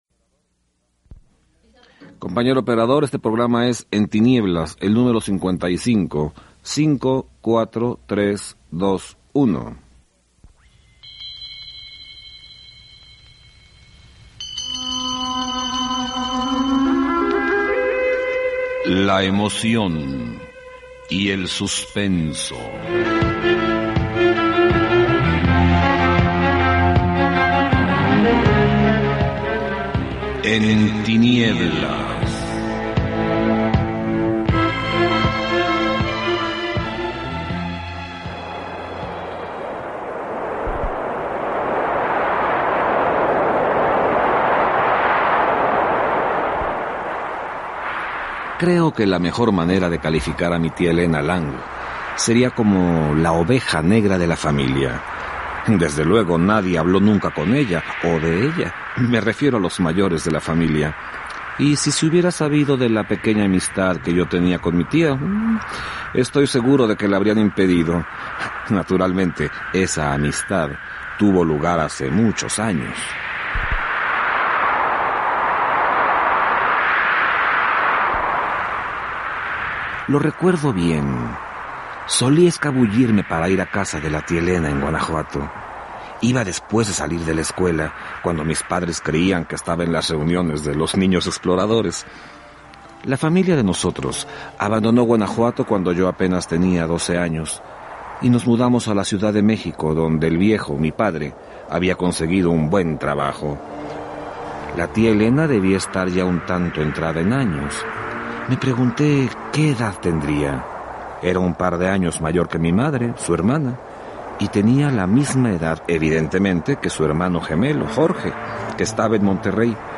Escucha “La tía Esther” de Brian Lumley, en una adaptación libre de Javier Negrete, en el programa “En tinieblas”, transmitido en 1989.